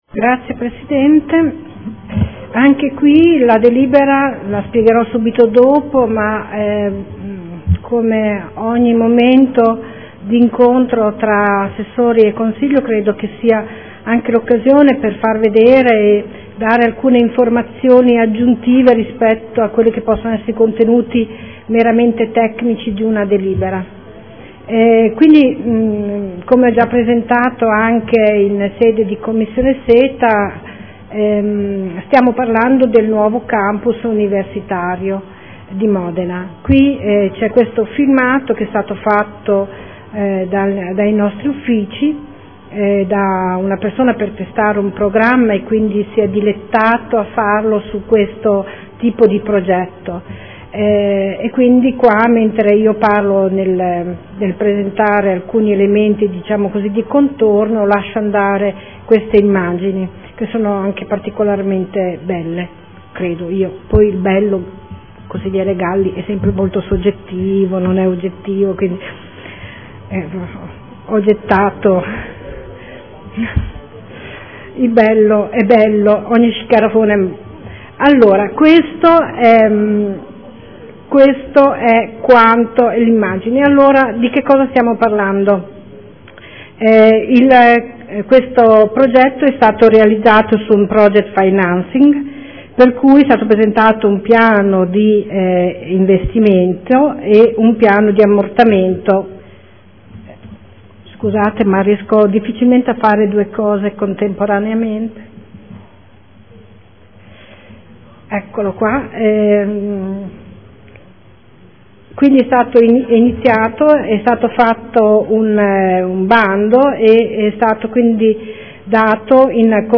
Anna Maria Vandelli — Sito Audio Consiglio Comunale